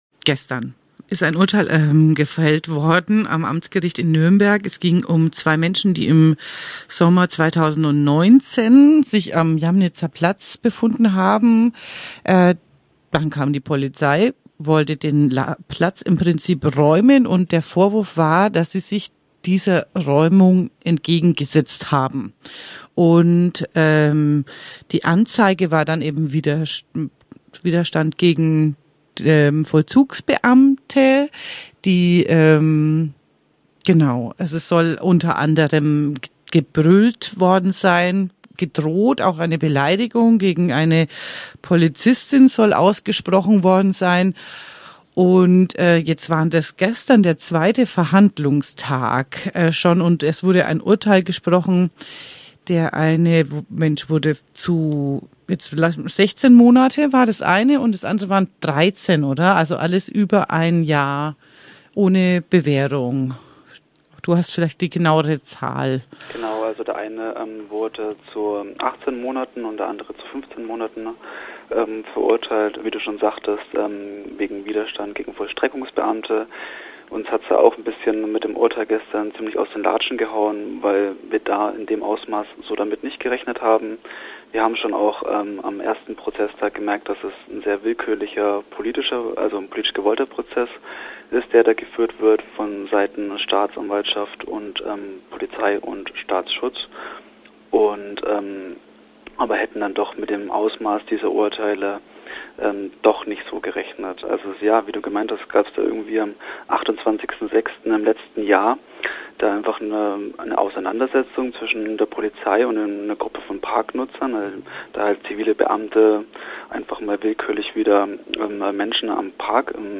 jaminitzer-interview-radioZ-0710-2.mp3